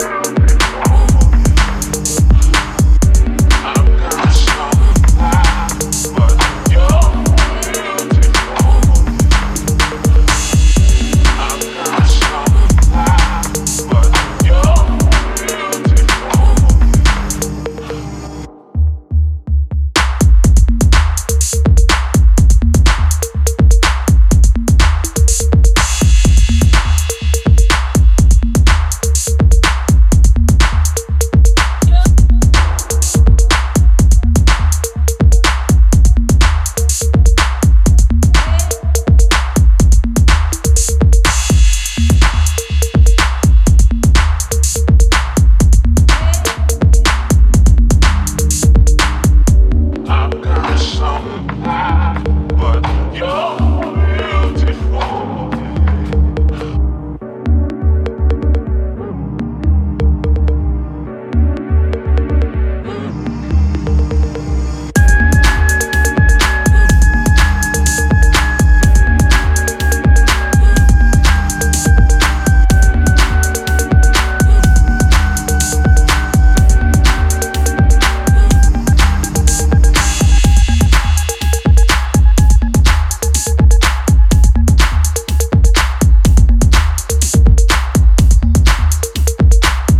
straight up house jams, hints of techno, nods to broken beat